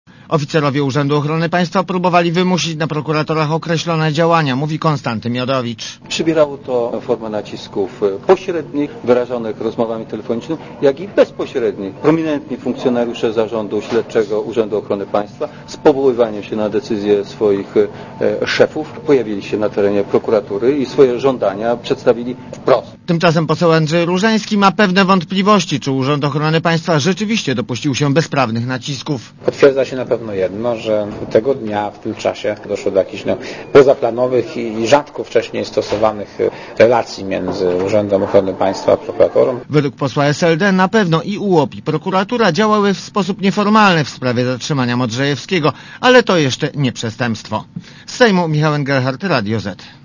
Relacja reportera Radia ZET Obie prokurator - przełożone Katarzyny Kalinowskiej , która 7 lutego 2002 r. wydała nakaz zatrzymania Modrzejewskiego - przesłuchiwane były na posiedzeniu tajnym.